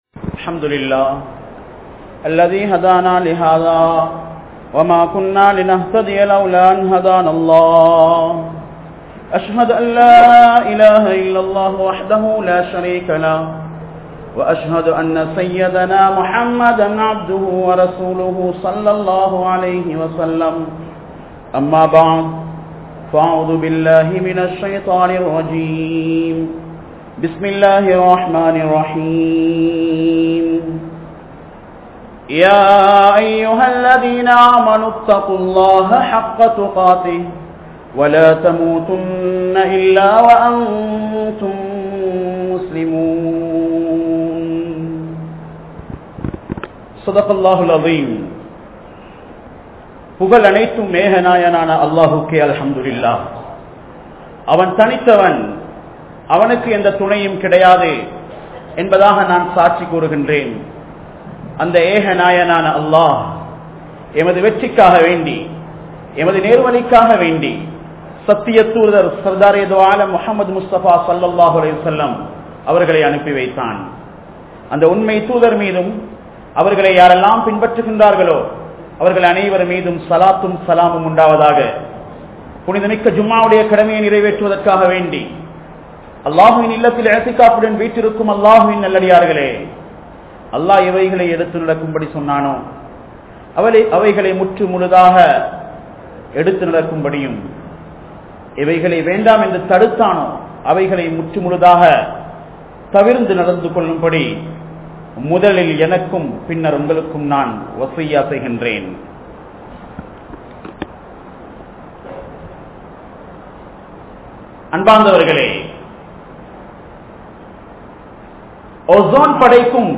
Aniyaayam Seithavarhal Alinthu Poavaarhal | Audio Bayans | All Ceylon Muslim Youth Community | Addalaichenai
Colombo 11, Samman Kottu Jumua Masjith (Red Masjith)